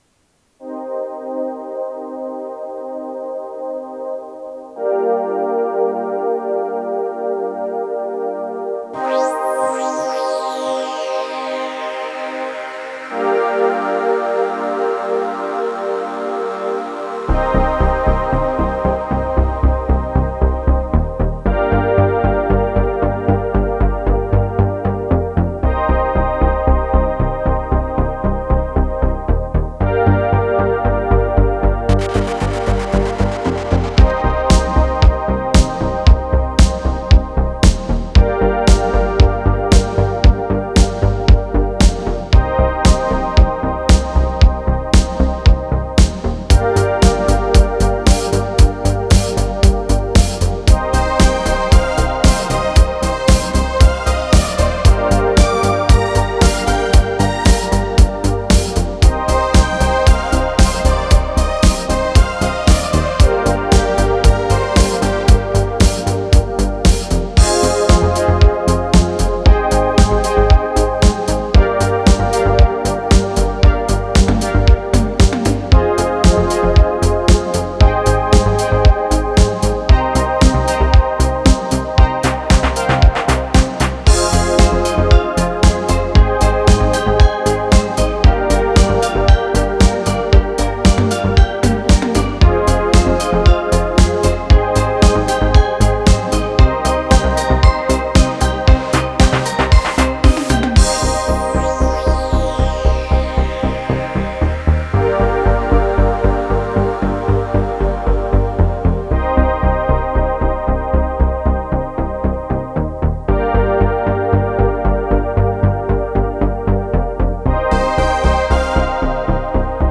A chilled Synthwave Track to dream to.